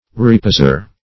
reposer - definition of reposer - synonyms, pronunciation, spelling from Free Dictionary Search Result for " reposer" : The Collaborative International Dictionary of English v.0.48: Reposer \Re*pos"er\ (r[-e]*p[=o]z"[~e]r), n. One who reposes.